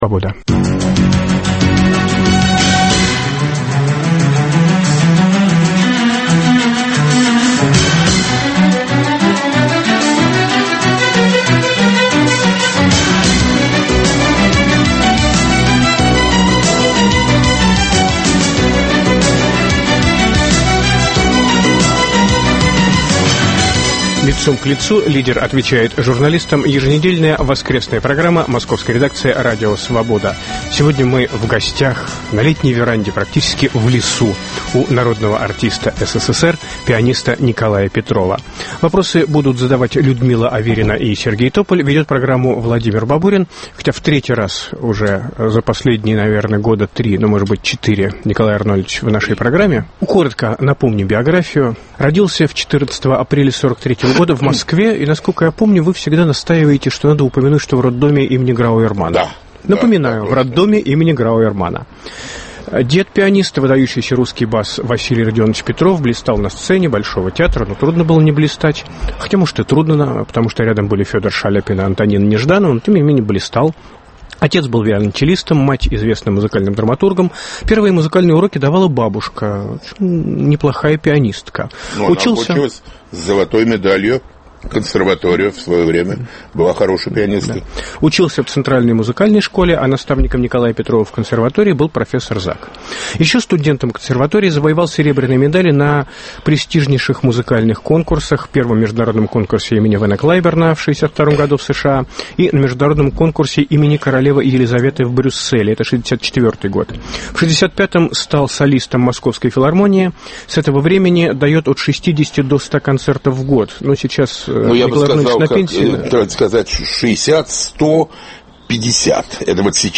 Лидер в той или иной сфере общественной жизни - человек известный и информированный - под перекрестным огнем вопросов трех журналистов: российского, иностранного и ведущего "Свободы".